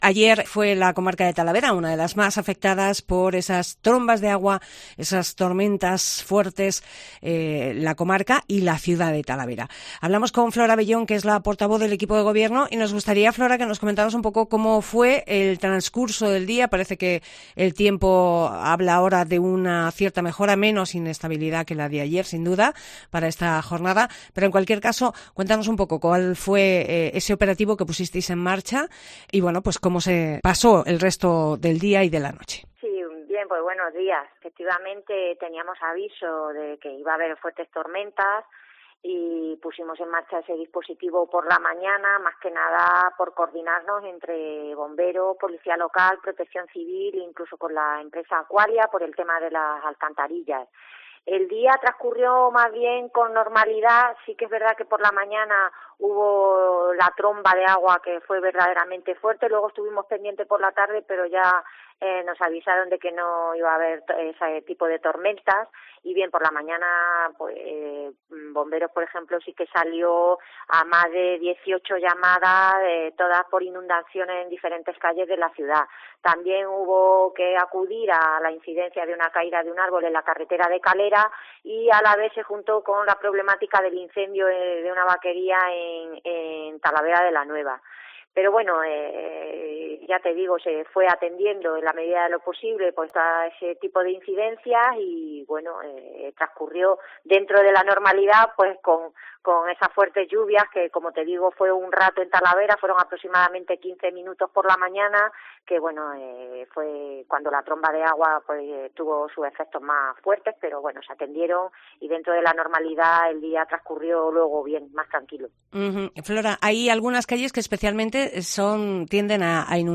Declaraciones de Flora Bellón, portavoz municipal